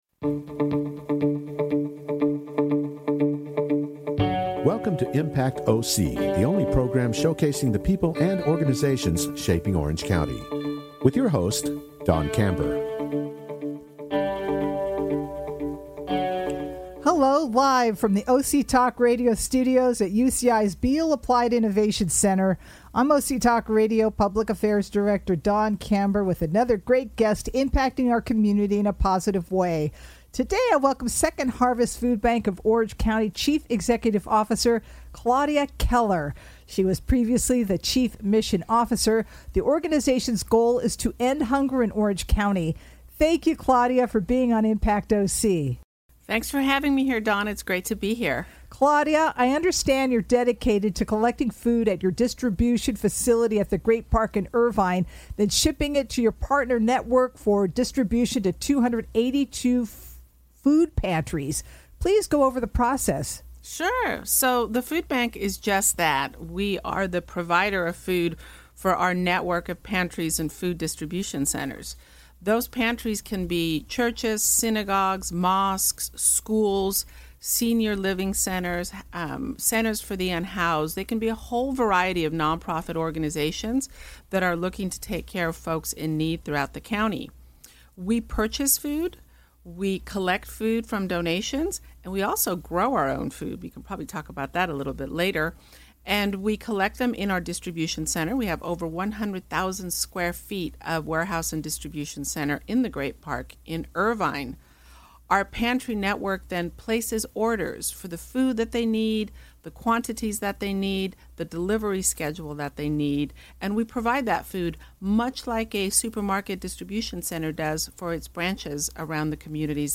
Only on OC Talk Radio, Orange County’s Only Community Radio Station which streams live from the University of California-Irvine’s BEALL APPLIED INNOVATION CENTER.